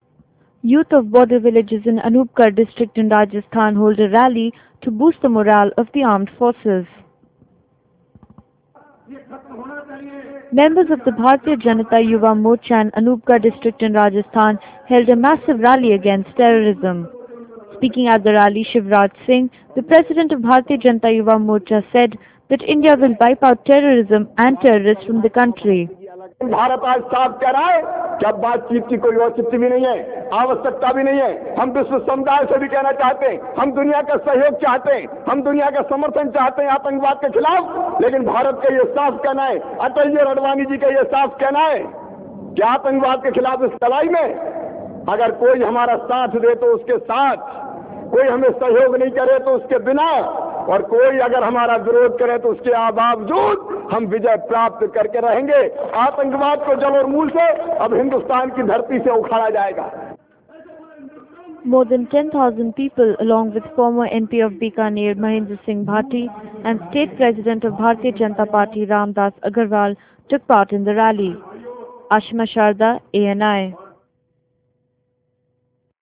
Youth of border villages in Anupgarh district, Rajasthan, hold a rally to boost the morale of armed forces.